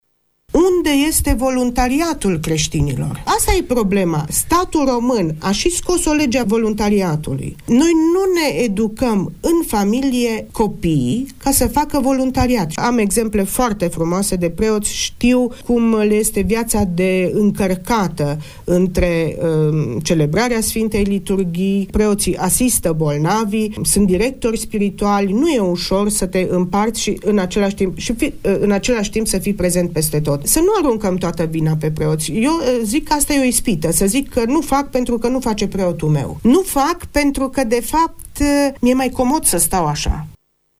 La Radio Tg Mureș, ascultătorii și privitorii secțiunii video fm de pe site au participat la o discuție despre responsabilitatea bisericii față de creștini, dar și a cetățenilor față de biserică.